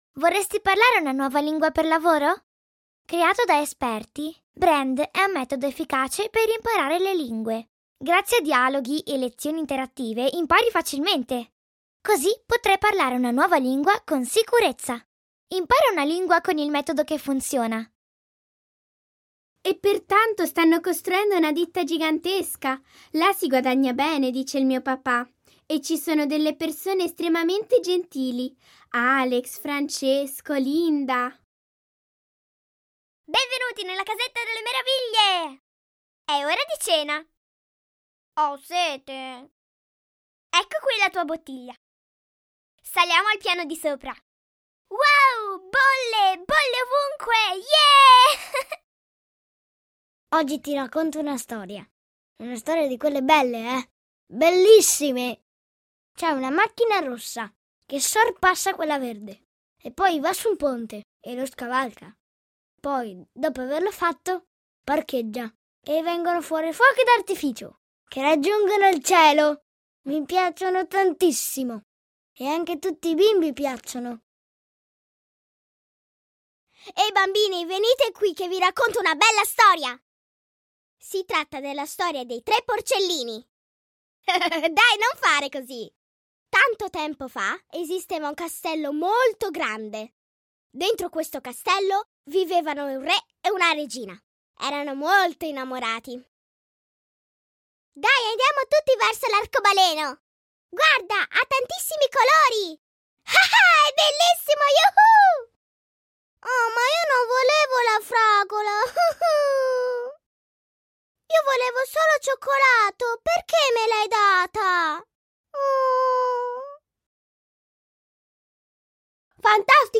Avatar Coty Cologne Voice Over Commercial Actor + Voice Over Jobs